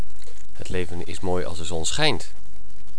accent op schijnt
leven-schijnt.wav